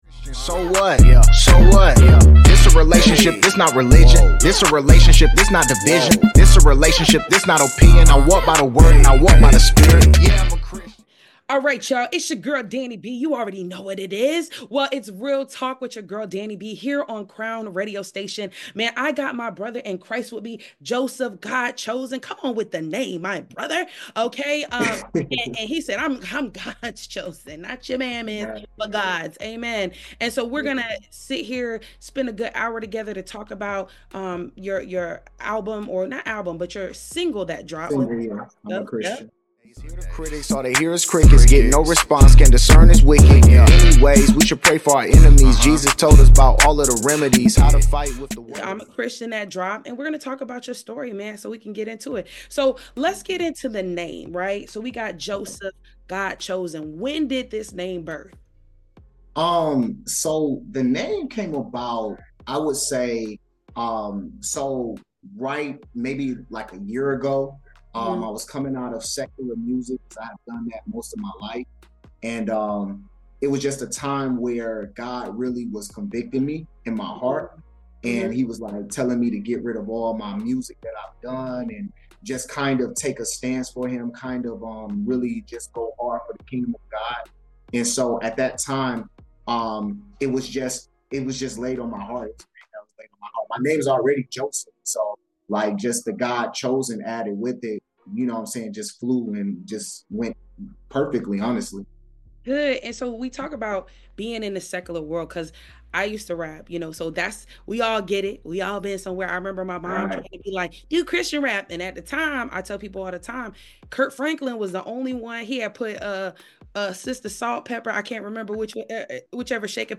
sits down for an inspiring and raw conversation